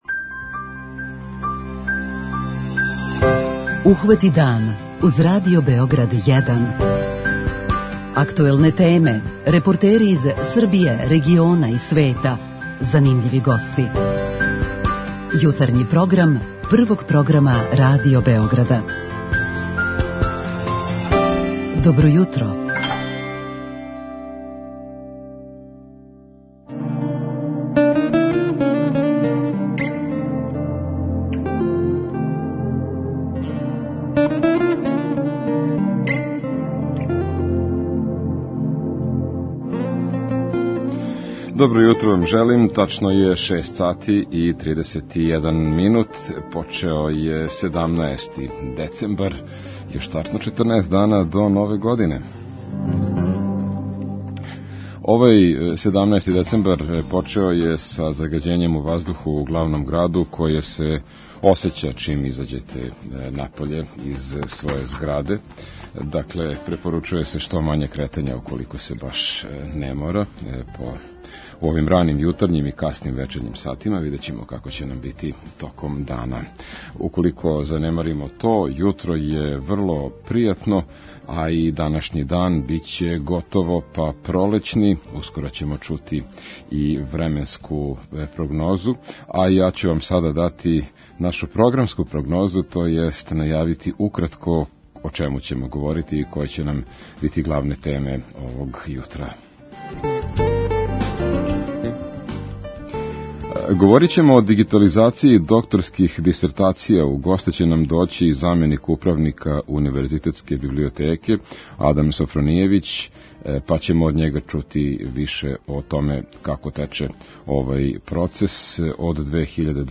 Јутарњи програм у уторак, 17.12. обележиће неколико тема: